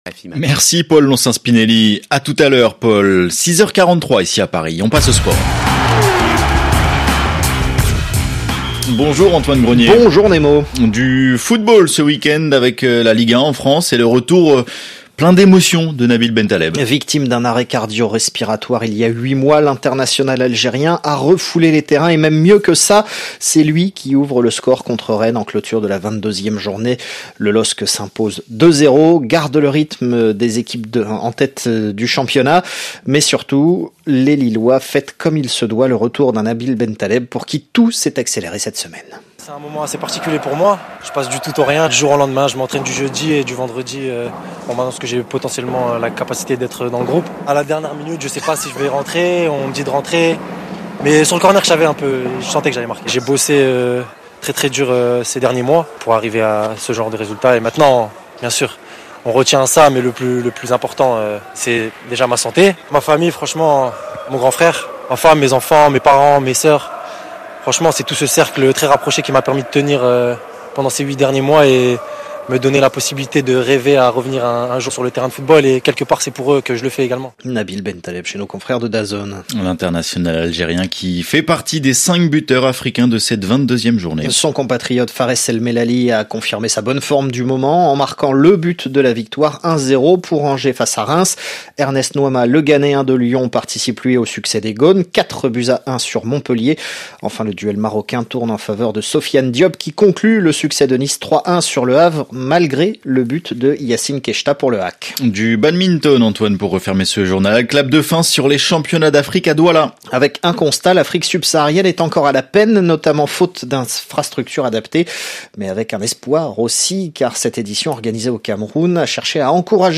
Retrouvez tous les journaux diffusés sur l’antenne de RFI pour suivre l’actualité africaine.